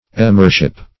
Emirship \E`mir*ship\